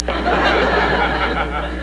Crowd Sound Effect
crowd.mp3